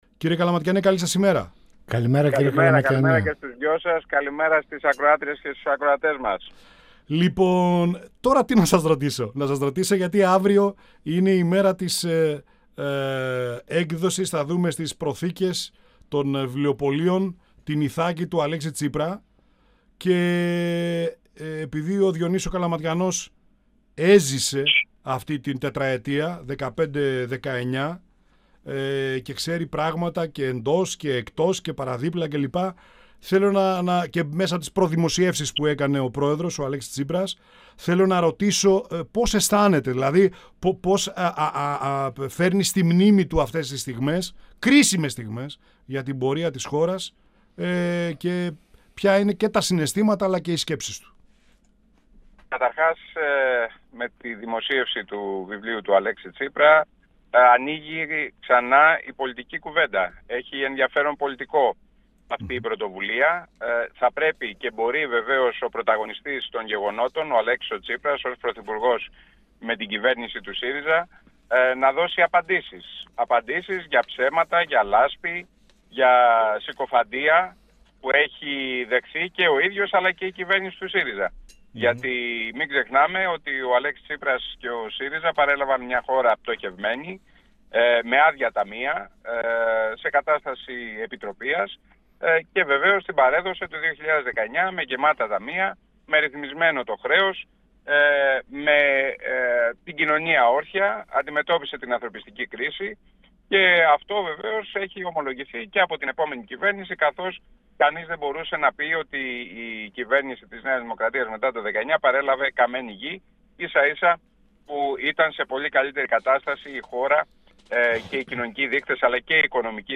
Στη κινούμενη άμμο στον ευρύτερο χώρο της Κεντροαριστεράς, με αφορμή το βιβλίο «Ιθάκη» του Αλέξη Τσίπρα, τις δημοσκοπήσεις και τις επερχόμενες αγροτικές κινητοποιήσεις σε σχέση με το σκάνδαλο του ΟΠΕΚΕΠΕ, αναφέρθηκε ο Γραμματέας της Κοινοβουλευτικής Ομάδας του ΣΥΡΙΖΑ Διονύσης Καλαματιανός, μιλώντας στην εκπομπή «Πανόραμα Επικαιρότητας» του 102FM της ΕΡΤ3.